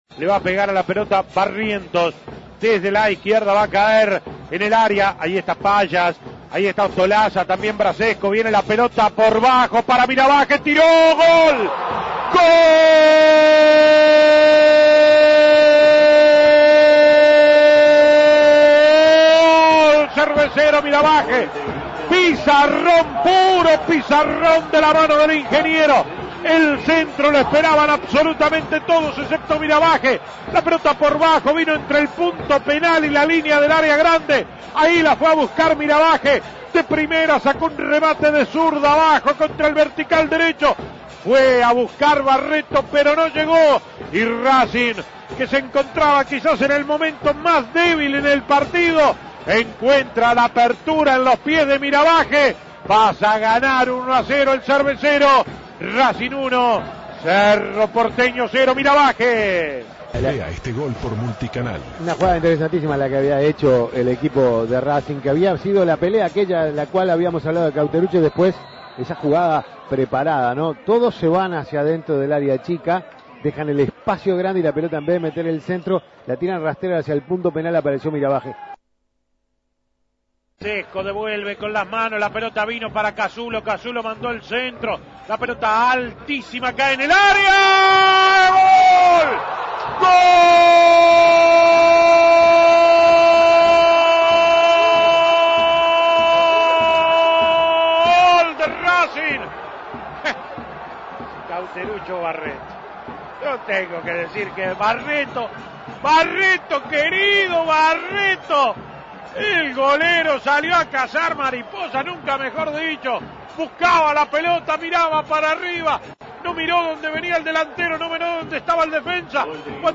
Goles y comentarios ESCUCHE LOS GOLES DE RACING A BANFIELD Imprimir A- A A+ Racing logró una gran victoria 2 a 1 sobre Cerro Porteño por la Copa Libertadores.